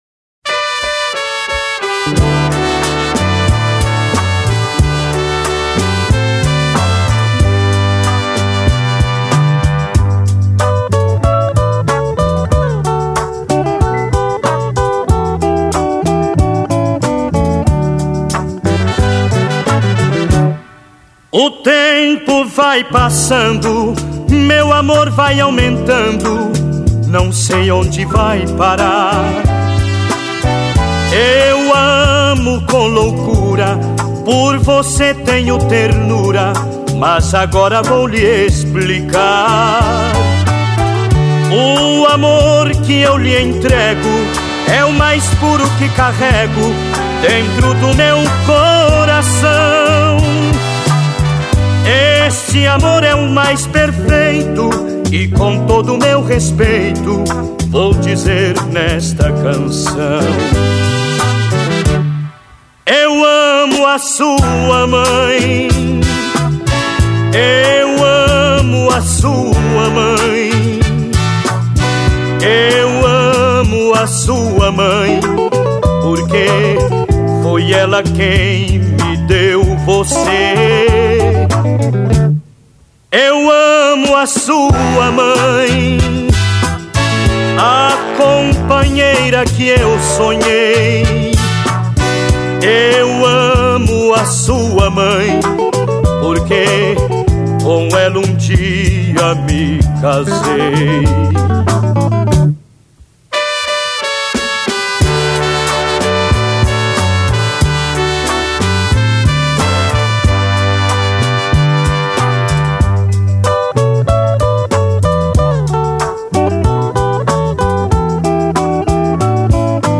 2024-02-20 23:39:18 Gênero: MPB Views